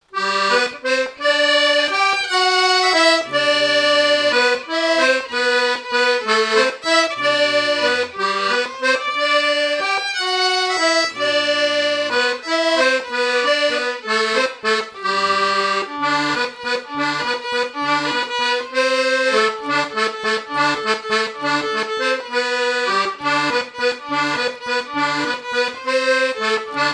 Mémoires et Patrimoines vivants - RaddO est une base de données d'archives iconographiques et sonores.
danse : valse
Quadrille de Rochetrejoux et danses populaires
Pièce musicale inédite